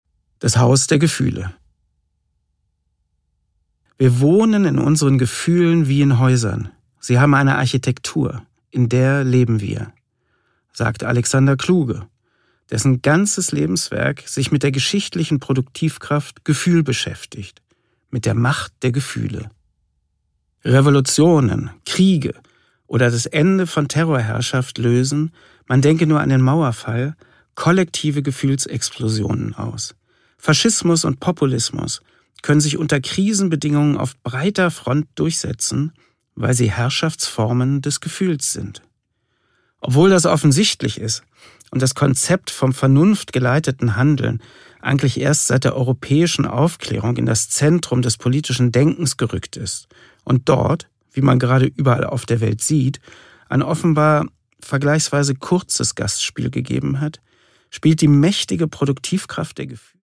Produkttyp: Hörbuch-Download
Gelesen von: Prof. Dr. Harald Welzer